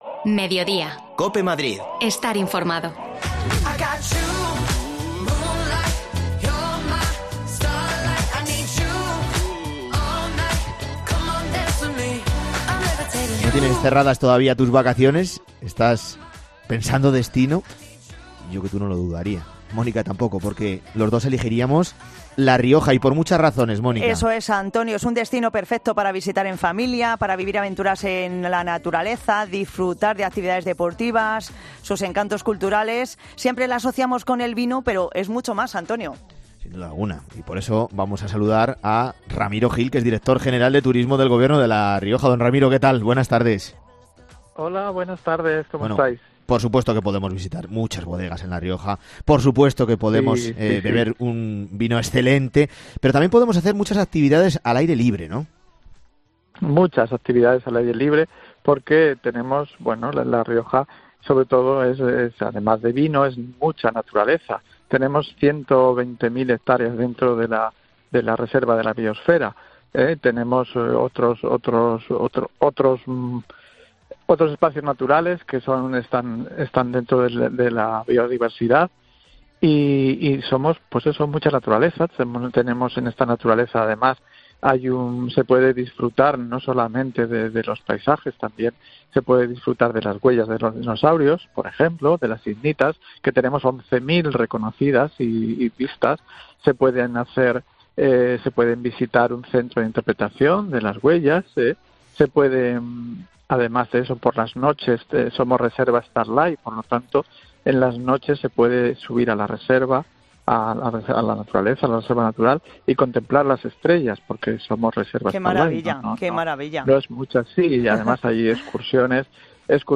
Ramiro Gil es Director General de Turismo en La Rioja, y en la mañana de hoy ha querido contar en Mediodía COPE las diferentes ofertas que la tierra riojana es capaz de ofrecer a sus visitantes.